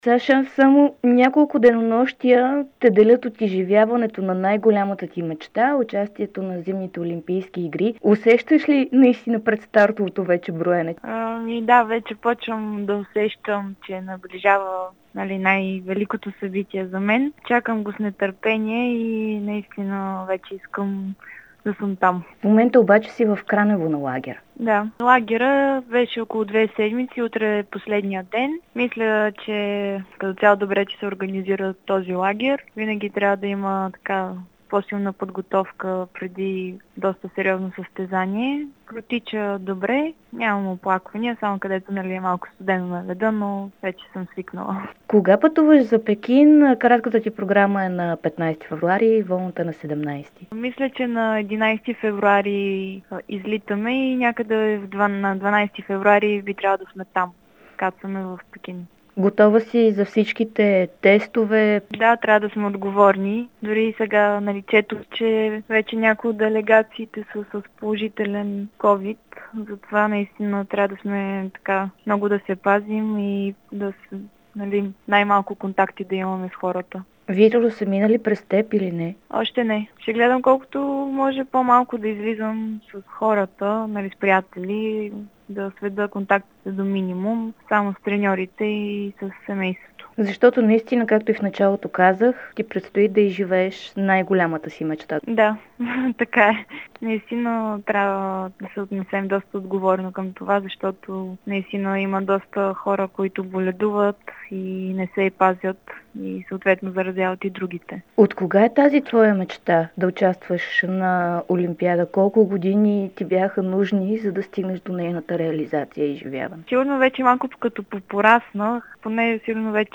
Преди да изживее най-голямата си мечта – да бъде на олимпийския лед в Пекин, Александра Фейгин даде специално интервю за Дарик радио и dsport от лагера в Кранево. Тя не скри, че операцията на коляното й пречи да е в най-добрата си форма, която коъм момента оценява на около 70%.